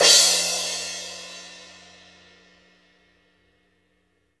CYM 15 DRK0P.wav